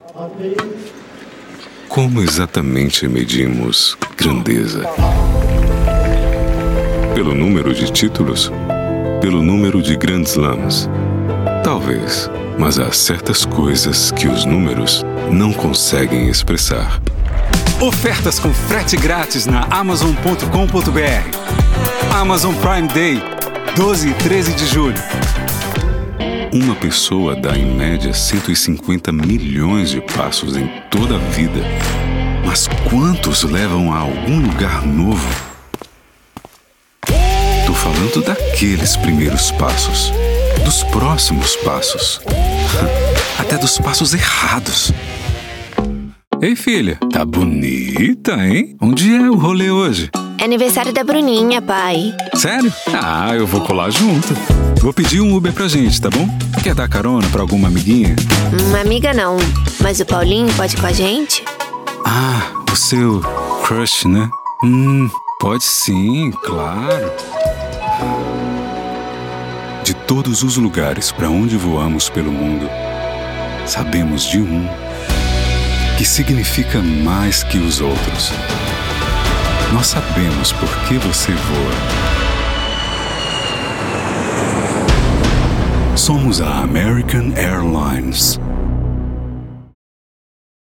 Démo commerciale
Ma voix est naturelle et professionnelle. Elle est souvent décrite comme crédible, veloutée et douce, inspirant confiance et calme à l'auditeur.
Microphone : Neumann TLM103
Cabine vocale acoustiquement isolée et traitée
BarytonBasseProfondBas